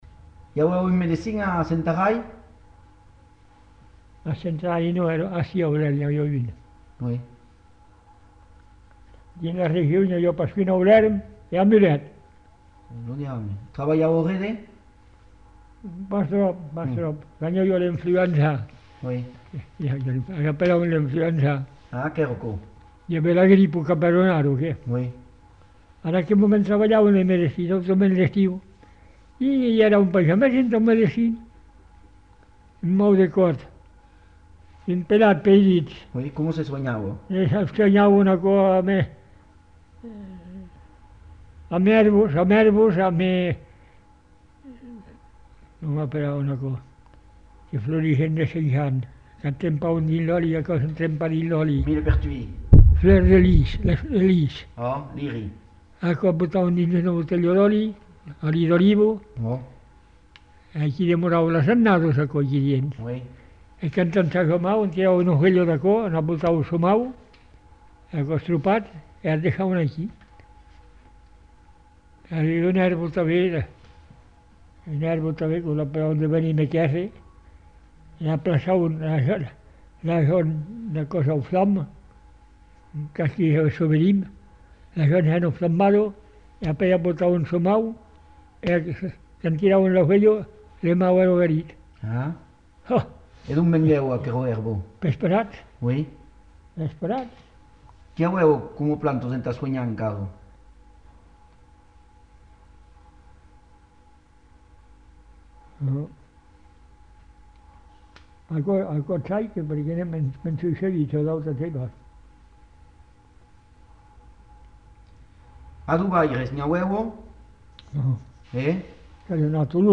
Lieu : Lherm
Genre : témoignage thématique